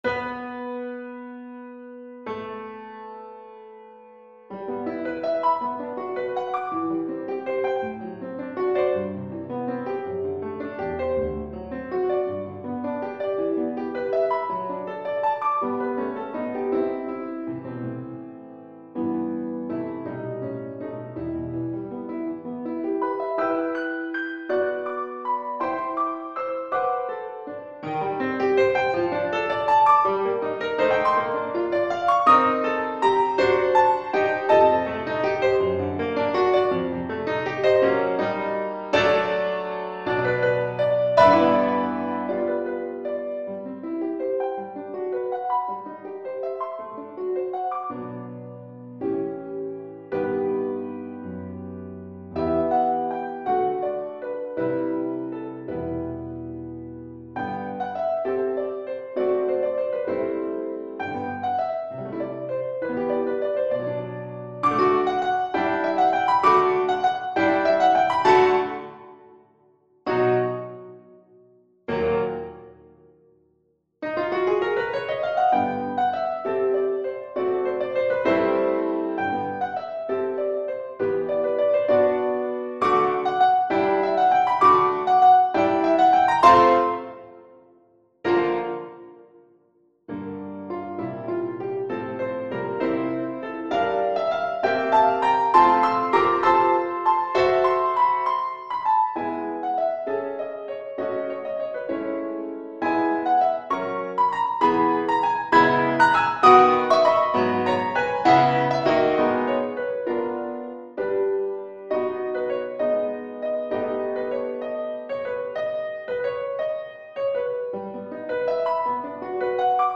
6/8 (View more 6/8 Music)
Classical (View more Classical Cello Music)